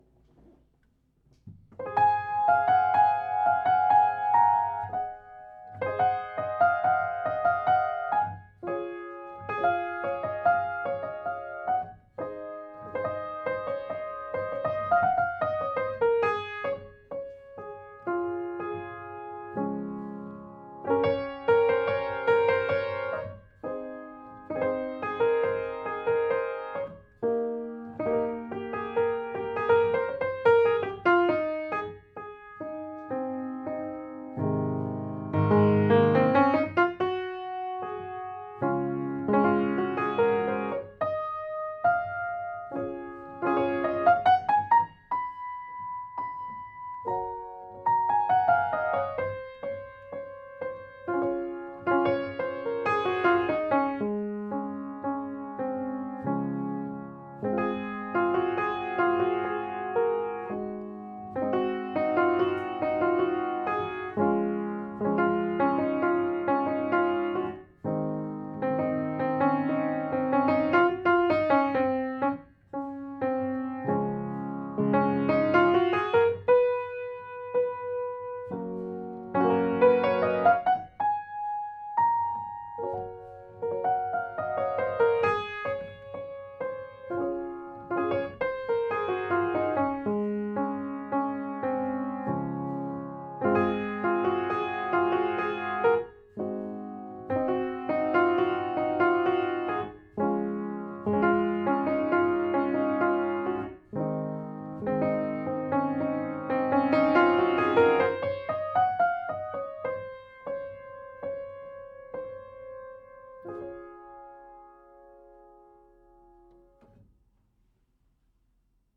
original piano melodies